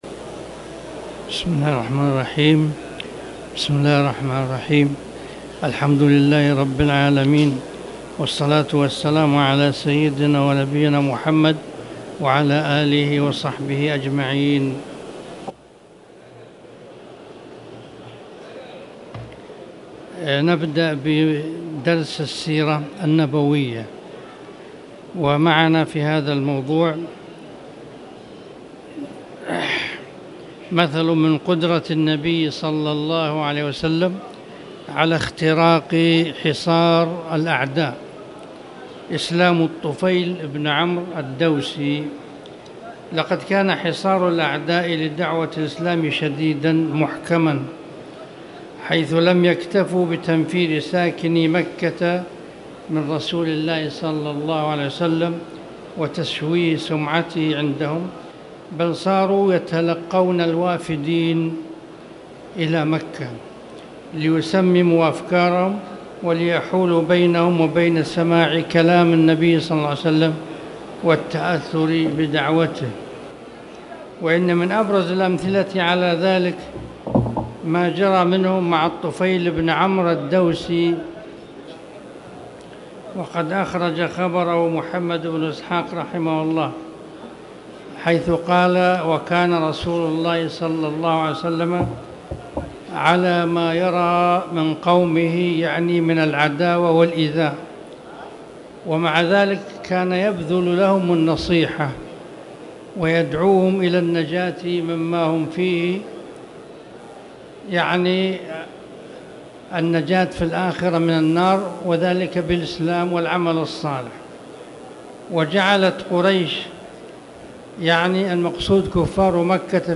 تاريخ النشر ١٠ جمادى الآخرة ١٤٣٨ هـ المكان: المسجد الحرام الشيخ